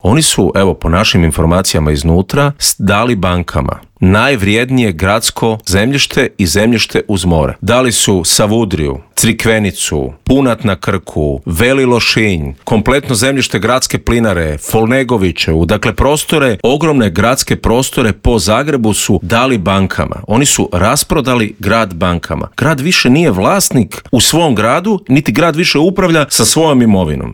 U studiju Media servisa ugostili smo nezavisnog kandidata za gradonačelnika Zagreba Davora Bernardića kojeg uvjerljivi trijumf Milanovića nije iznenadila jer su trendovi bili neupitni: